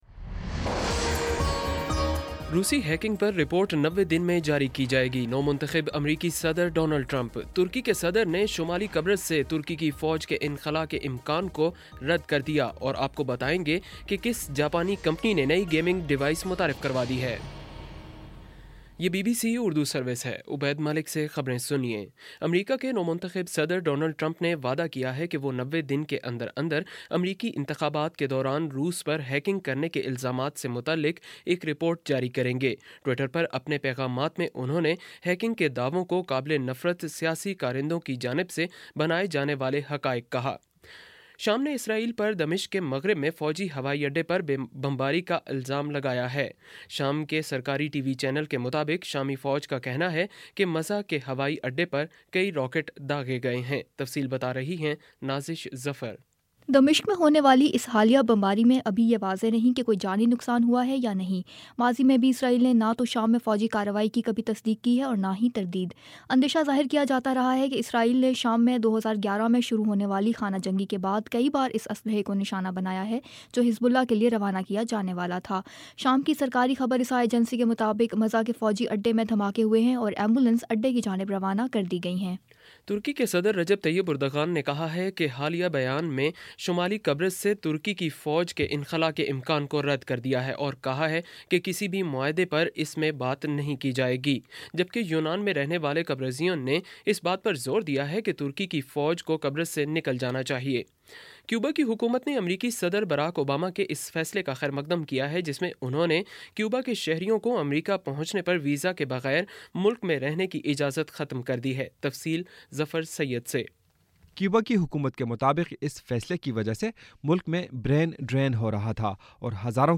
جنوری 13 : شام سات بجے کا نیوز بُلیٹن